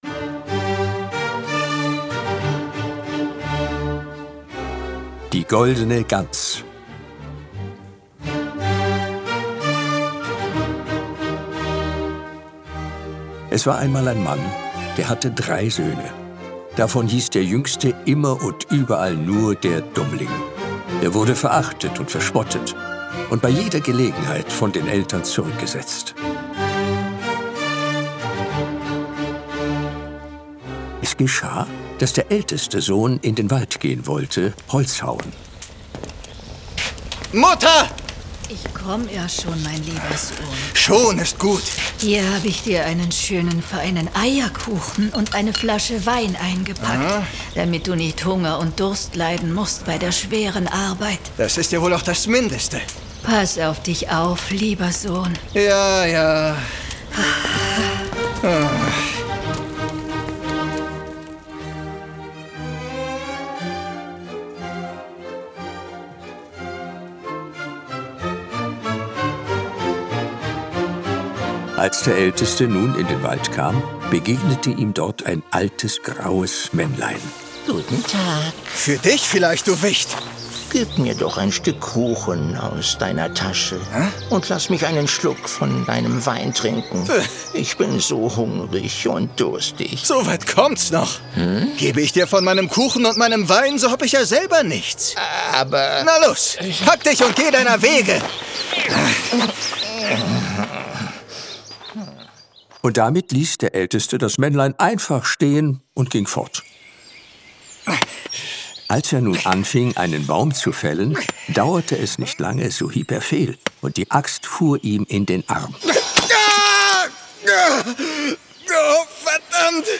Drei Hörspiele